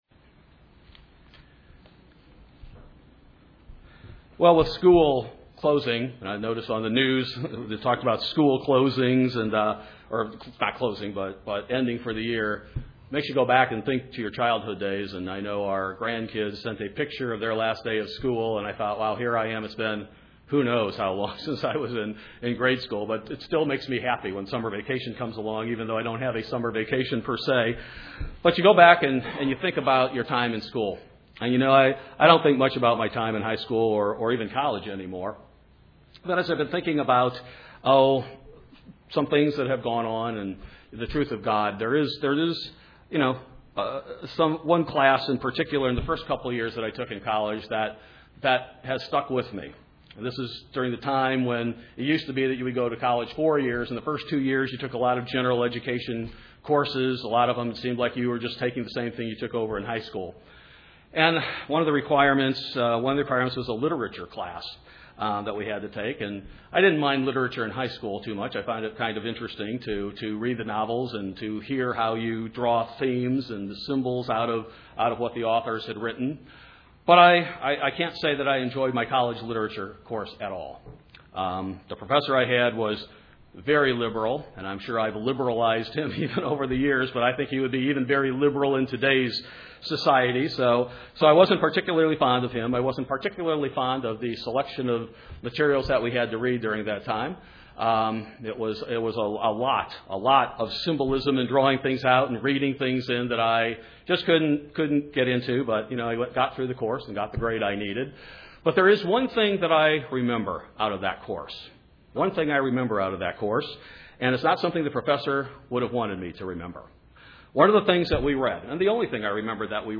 This sermon explores the concept of fact and fiction, truth and error, and good and evil, and how the true Christian can and will discern the difference.